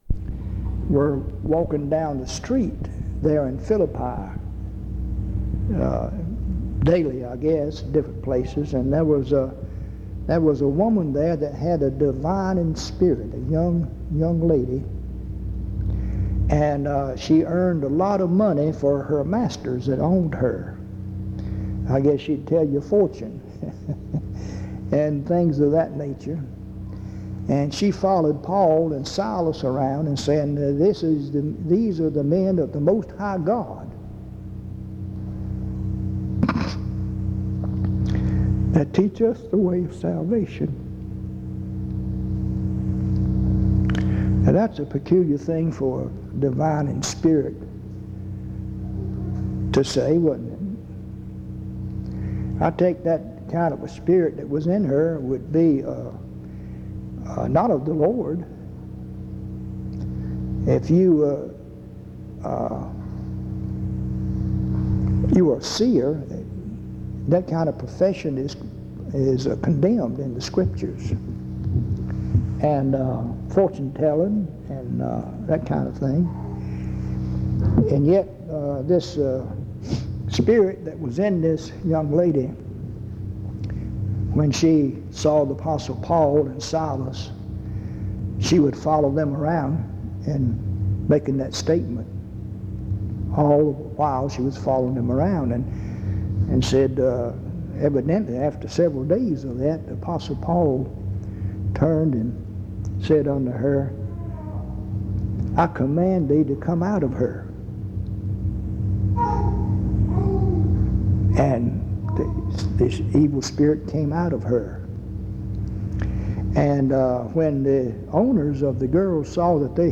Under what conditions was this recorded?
In Collection: Reidsville/Lindsey Street Primitive Baptist Church audio recordings Miniaturansicht Titel Hochladedatum Sichtbarkeit Aktionen PBHLA-ACC.001_075-A-01.wav 2026-02-12 Herunterladen PBHLA-ACC.001_075-B-01.wav 2026-02-12 Herunterladen